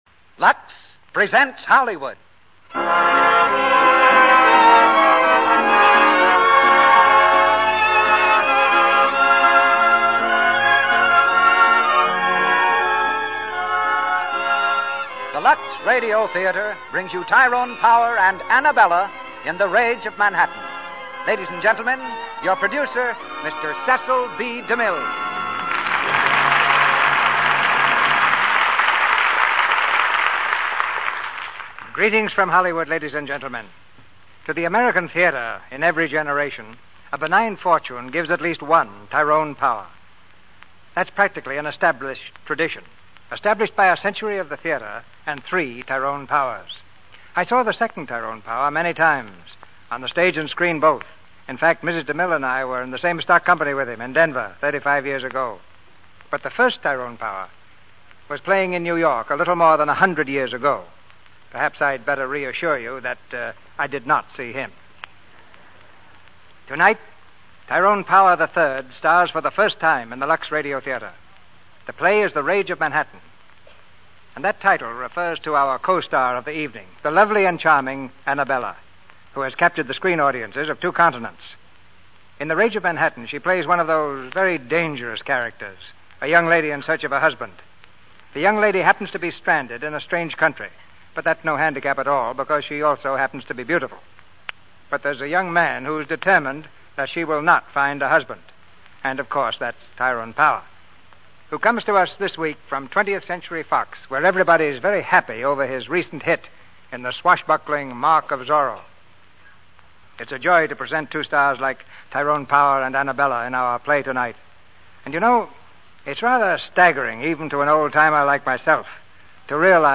Lux Radio Theater Radio Show